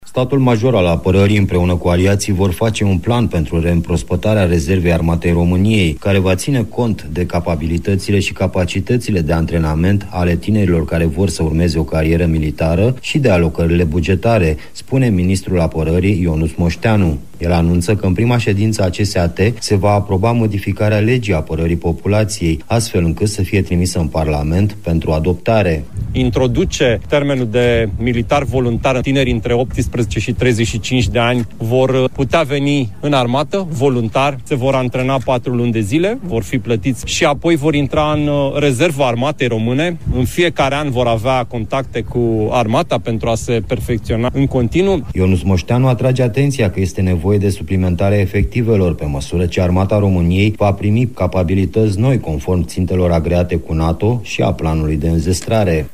Declarația a fost făcută la ceremonia împlinirii a 10 ani de la înființarea Comandamentului Multinaţional de Divizie Sud-Est NATO pe teritoriul României.